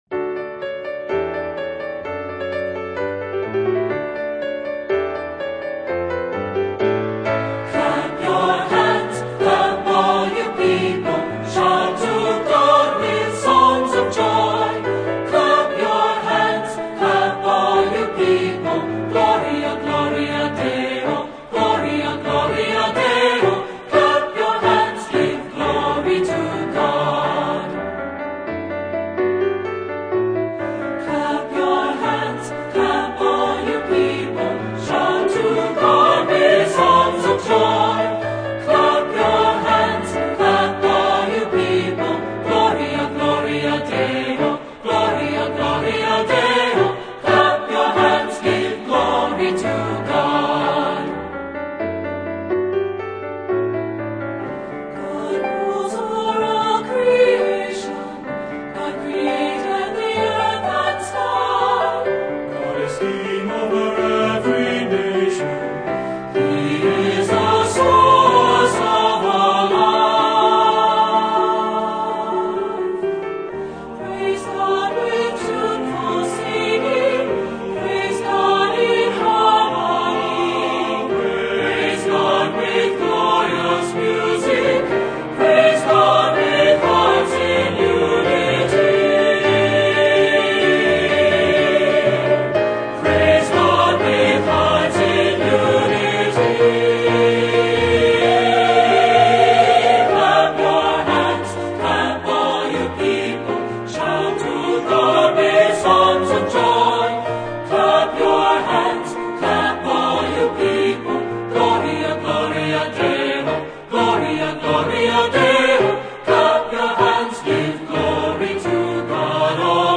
Voicing: SAB